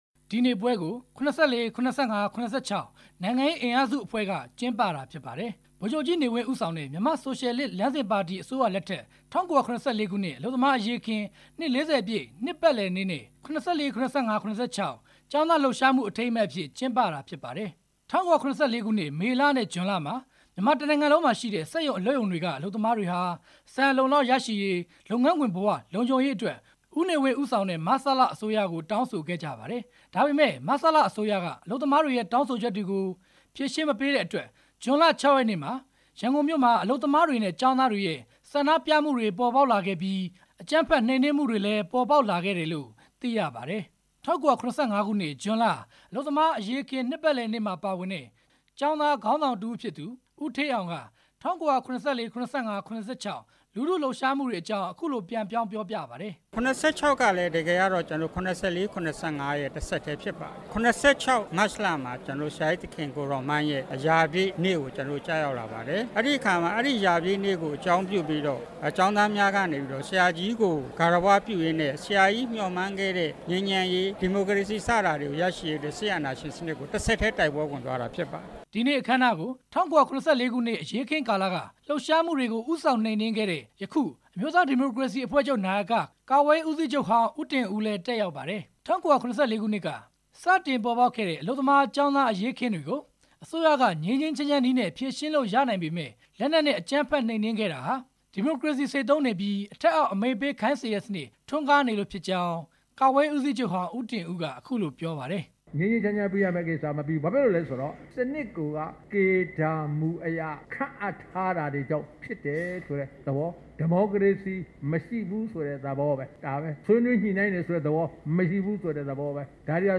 ၁၉၇၄ ၊ ၇၅ နဲ့ ၇၆ ခုနှစ်တွေမှာ ဖြစ်ပွားခဲ့ တဲ့ ကျောင်းသားလှုပ်ရှားမှုနဲ့ အလုပ်သမား အရေးအခင်းမှာ ပါဝင်ခဲ့သူတွေက ဒီနေ့ ရန်ကုန်မြို့ တော်ဝင် နှင်းဆီခန်းမမှာ နှစ် ၄၀ ပြည့် အထိမ်းအမှတ်ပွဲ ကျင်းပခဲ့ပါ တယ်။
ဒီနေ့ အခမ်းအနားကို တက်ရောက်ခဲ့တဲ့ ၈၈ မျိုးဆက် ငြိမ်းချမ်းရေးနဲ့ ပွင့်လင်းလူ့အဖွဲ့အစည်း ခေါင်းဆောင် ကိုကိုကြီးက လက်ရှိ မြန်မာနိုင်ငံရဲ့ အလုပ်သမားထု ရင်ဆိုင်နေရတဲ့ ပဋိပက္ခတွေကို အလုပ်သမားတွေ ကိုယ်တိုင် ဖြေရှင်းနိုင်တဲ့ အခြေအနေ အထိရောက်အောင် ကြိုးပမ်းကြရမှာ ဖြစ်တယ်လို့ အခုလို ပြောပါတယ်။